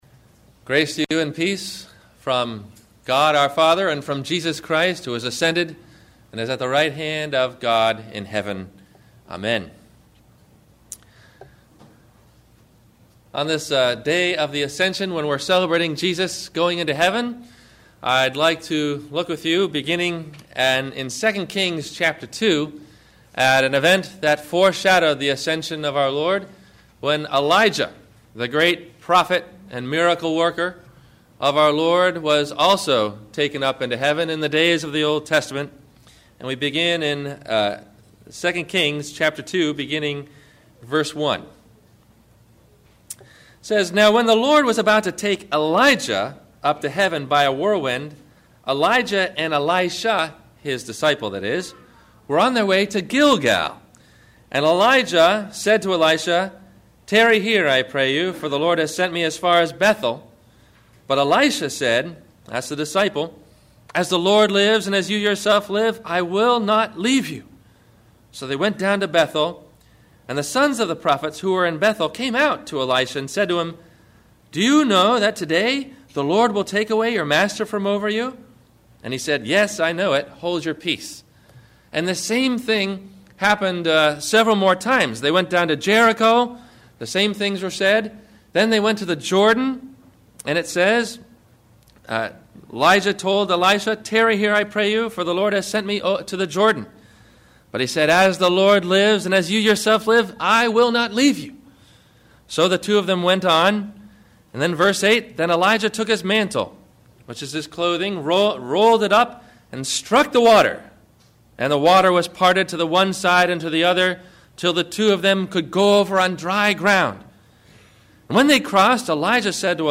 Listen for these questions and answers about “The Sovereignty of God”, below in the 1-part MP3 Audio Sermon below.